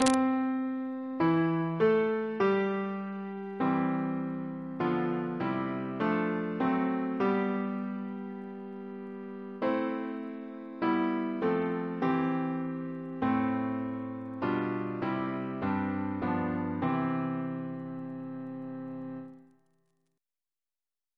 Double chant in C Composer: John C. Beckwith (1750-1809) Reference psalters: OCB: 76